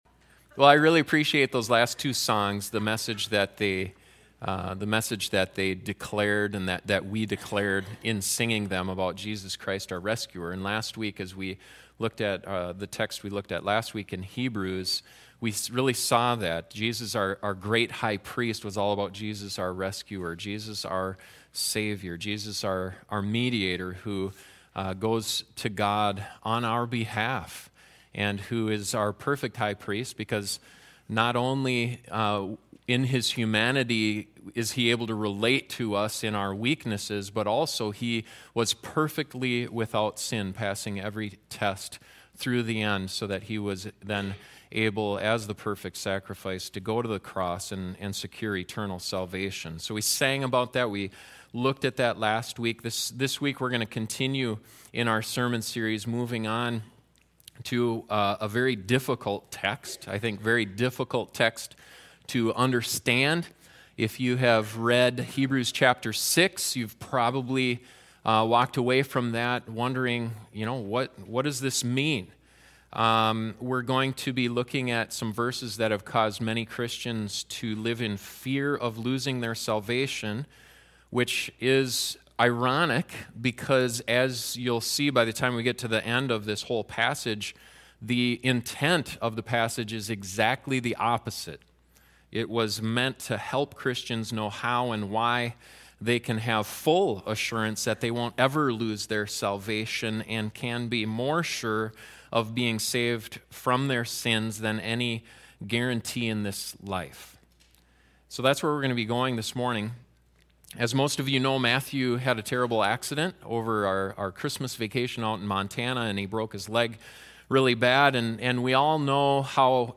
This sermon will cover some difficult verses that have caused many Christians to live in fear of losing their salvation. Can you lose your salvation?